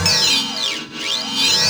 FX  XYLO S0H.wav